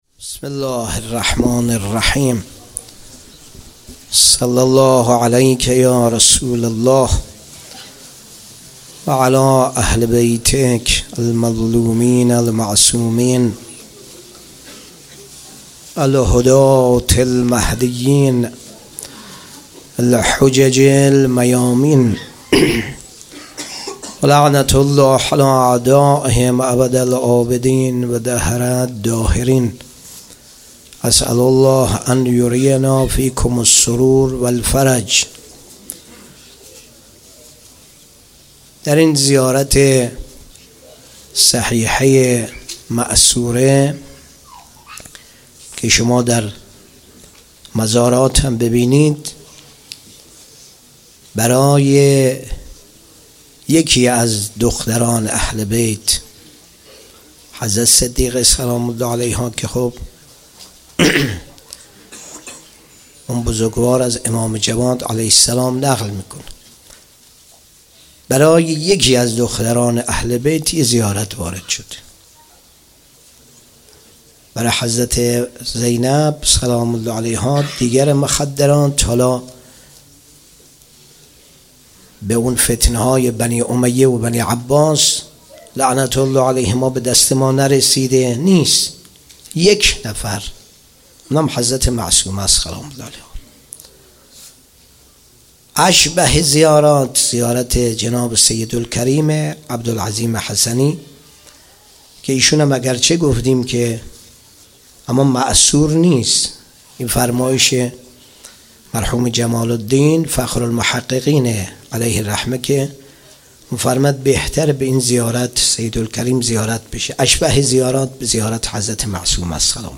شهادت حضرت معصومه - سخنرانی